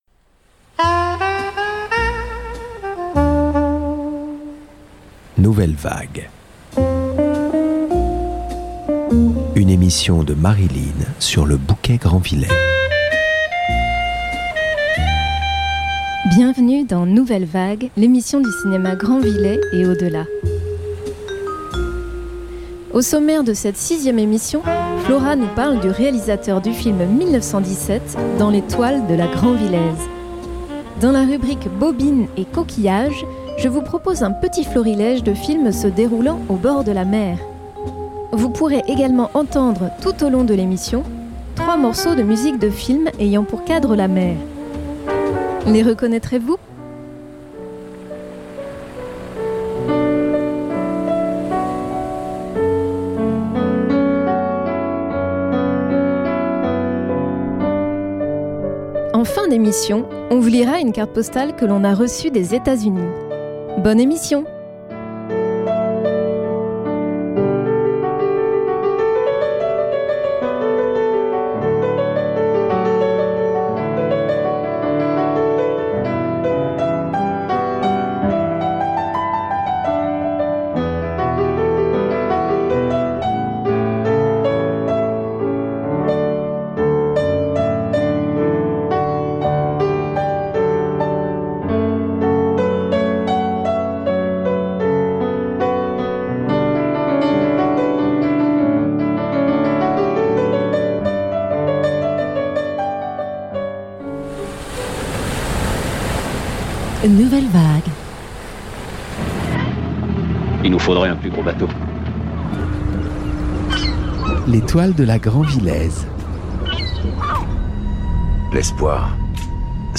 Vous pourrez également entendre tout au long de l’émission 3 morceaux de musiques films ayant pour cadre la mer.
Et, en fin d’émission, on vous lit une carte postale que l’on a reçue des Etats-Unis !